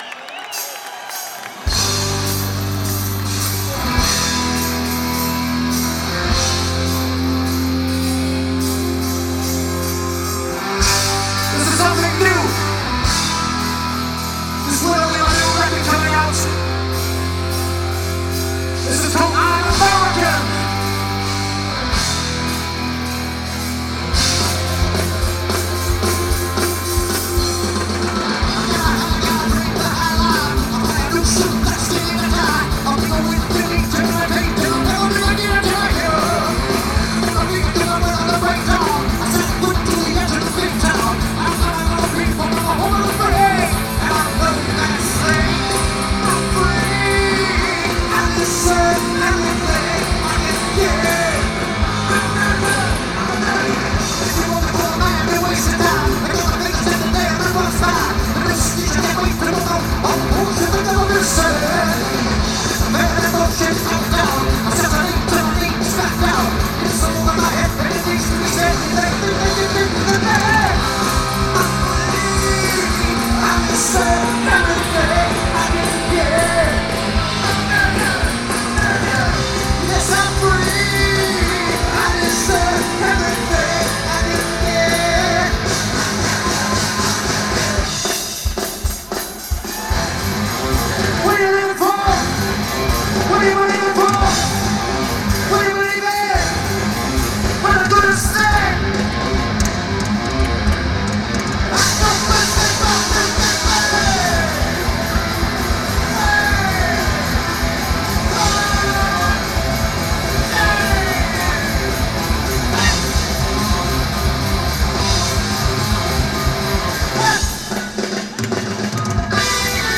Anyway, good quality, for what they are.